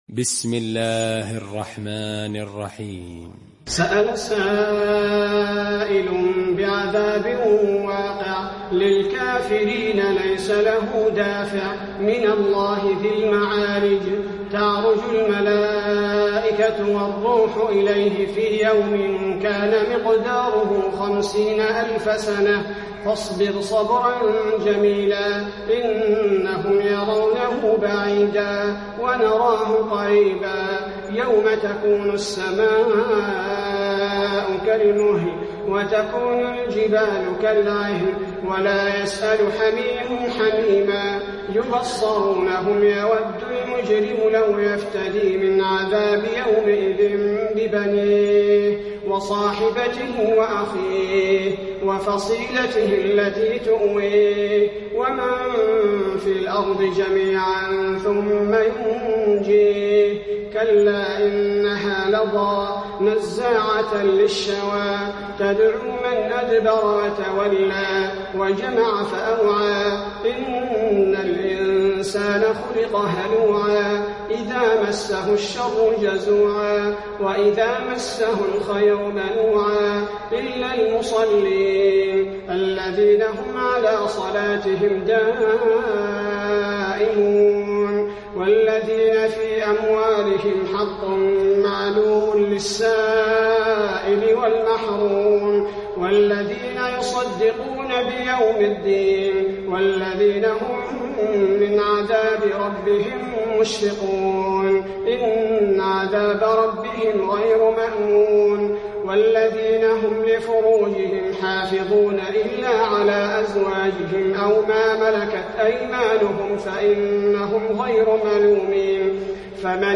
المكان: المسجد النبوي المعارج